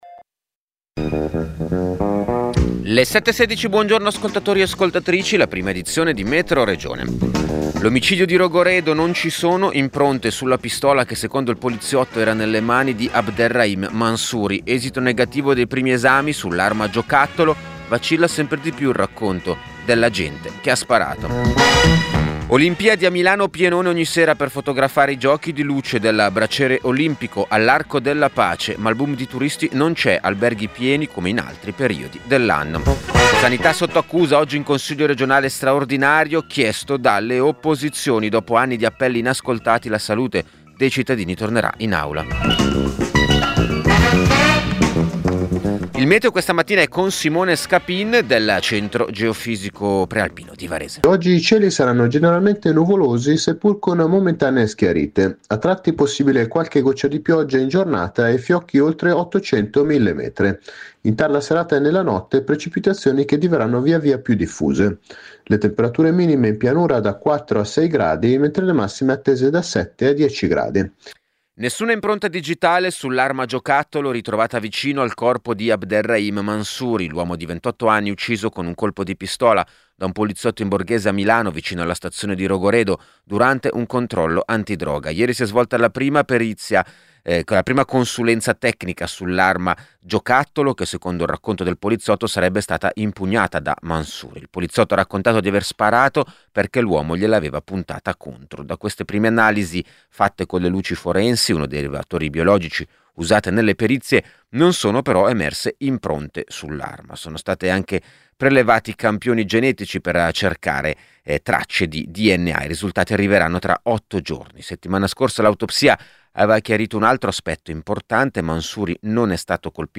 Metroregione è il notiziario regionale di Radio Popolare.